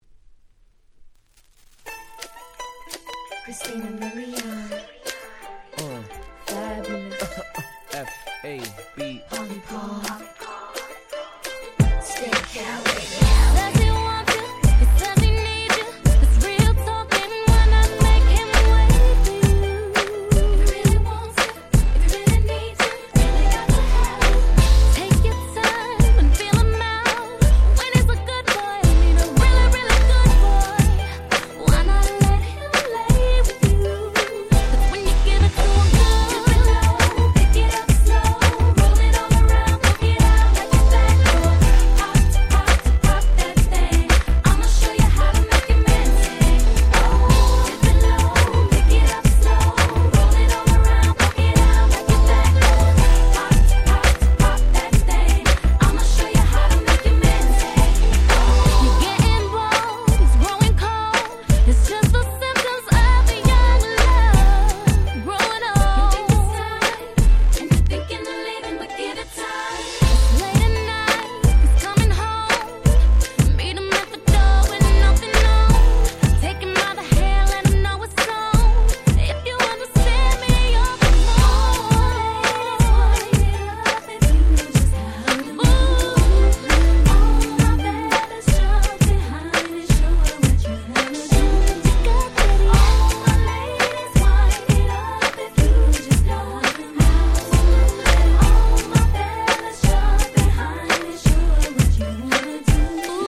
04' Super Hit R&B !!
Sexyな魅力あふれるオリエンタルなダンスチューン！！